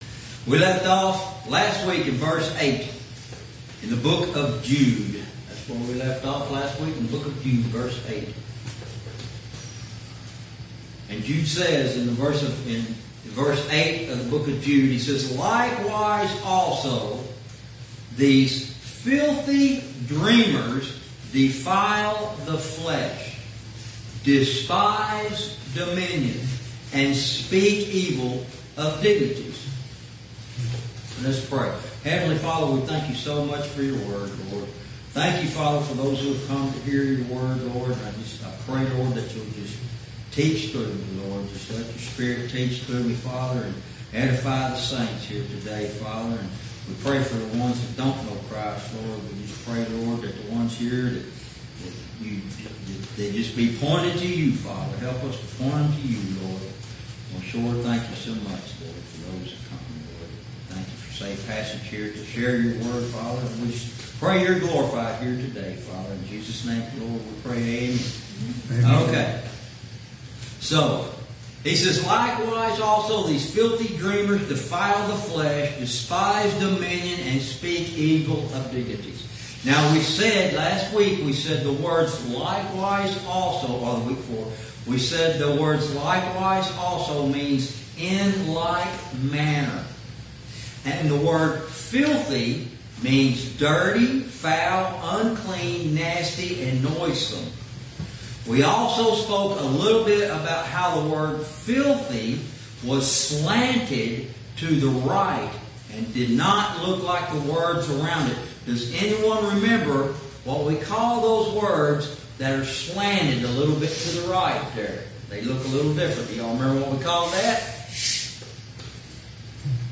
Verse by verse teaching - Lesson 24